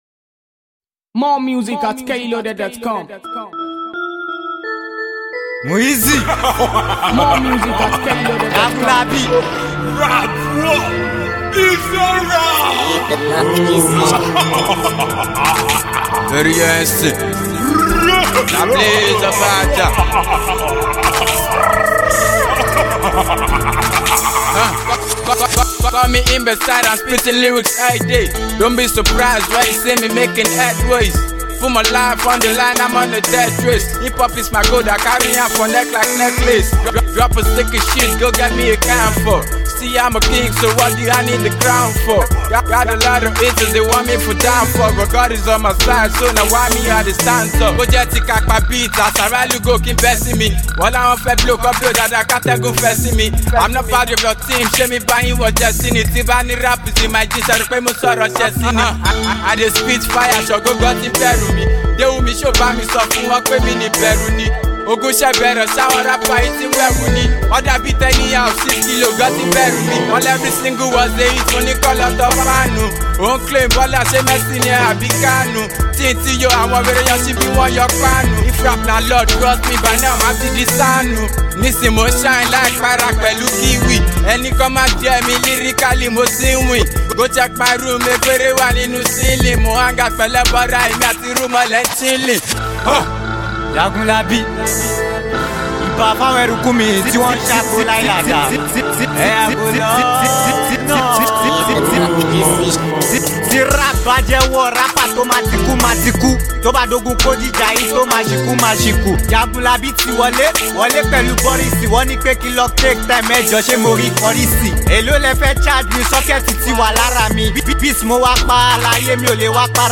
rap track